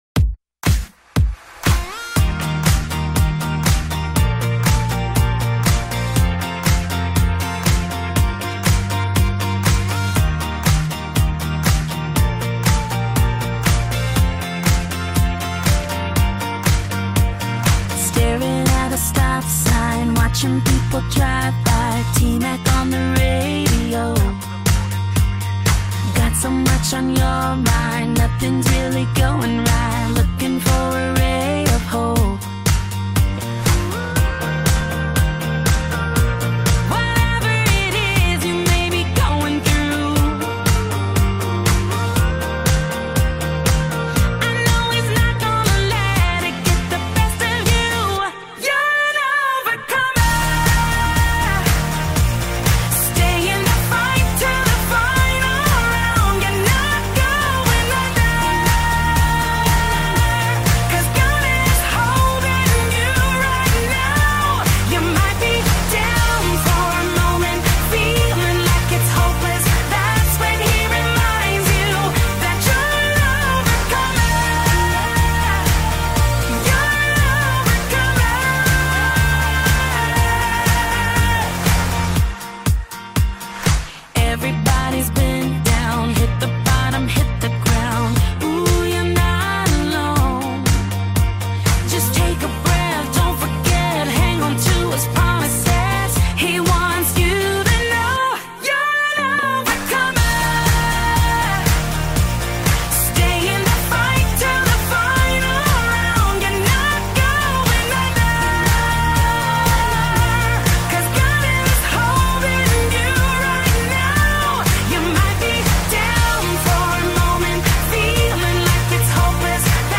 powerful gospel song